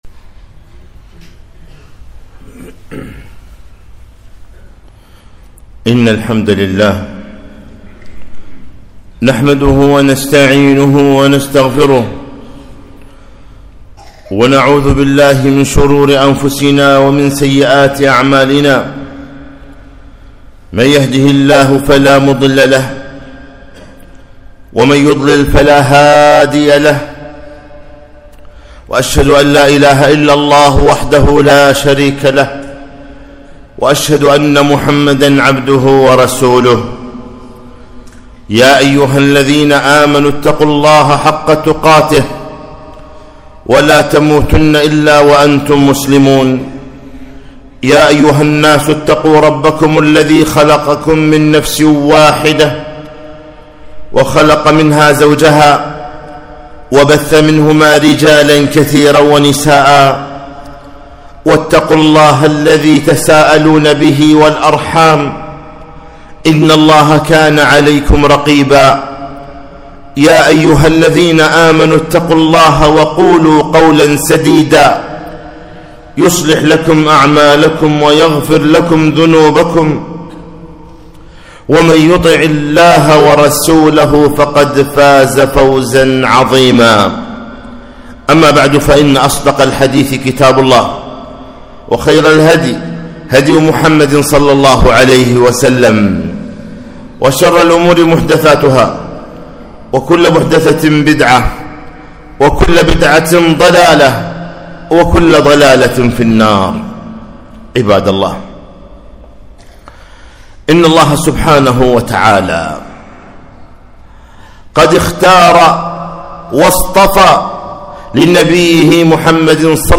خطبة - فضل الصحابة رضي الله عنهم